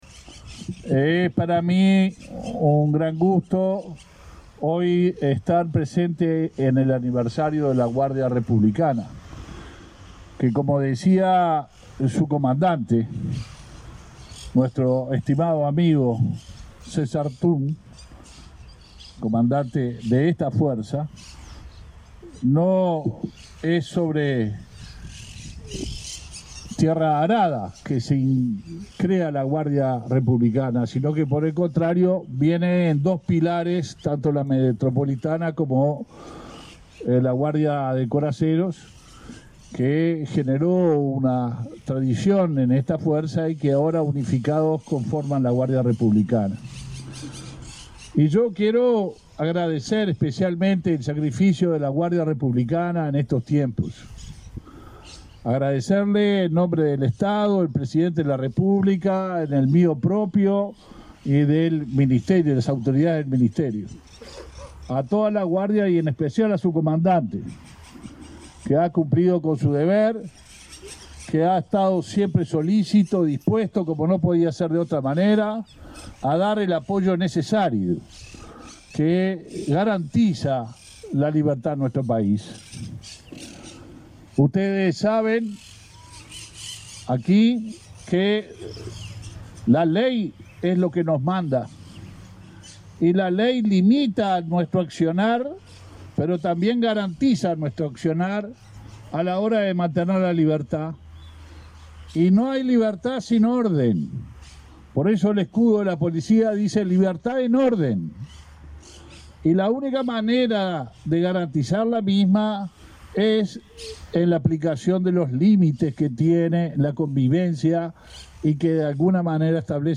Palabras del ministro del Interior, Luis Alberto Heber
El ministro del Interior, Luis Alberto Heber, participó este lunes 27 del acto aniversario de la Guardia Republicana.